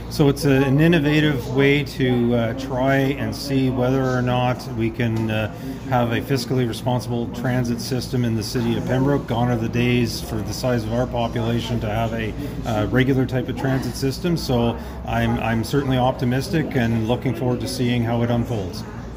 At a press conference held at Algonquin College
Pembroke Mayor Ron Gervais who has long supported the project is guardedly optimistic about the endeavour and is looking forward to seeing the metrics as they unfold during the pilot run: